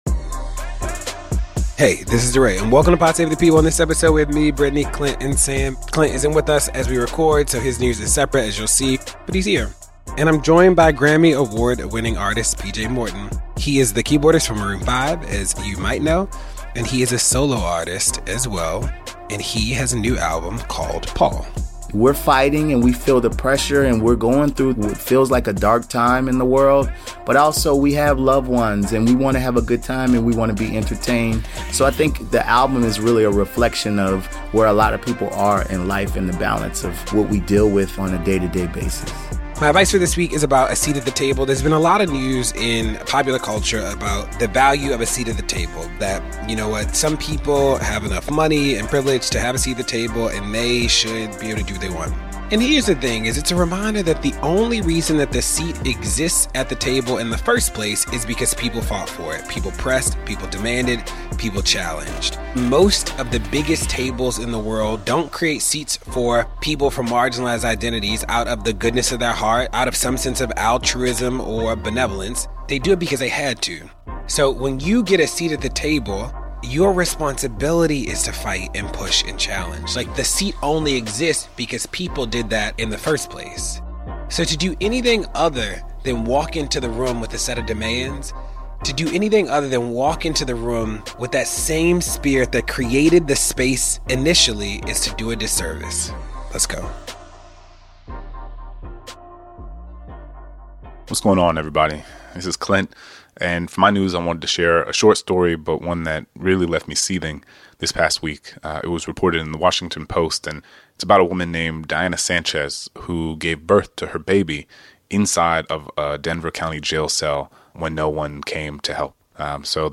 Grammy-award winning musician PJ Morton joins DeRay to talk about his new album, "PAUL."